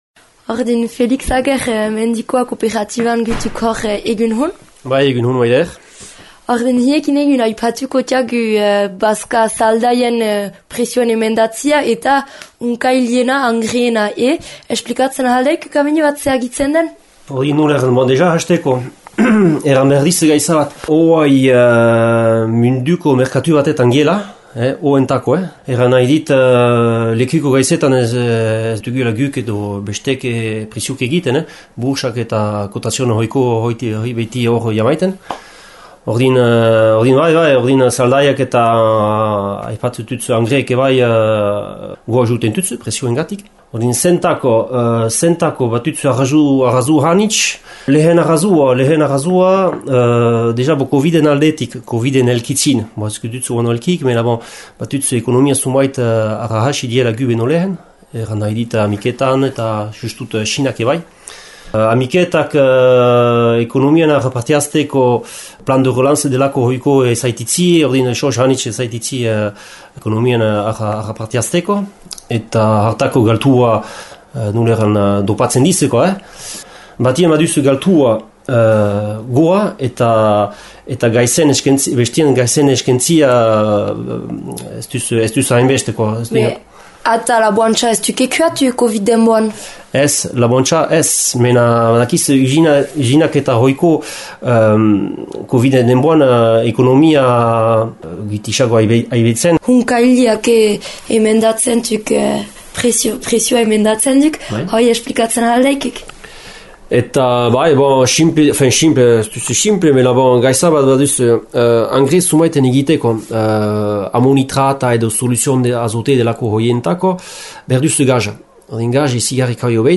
Mendikoa kooperatibako langileak horik oro esplikatzen deizkü: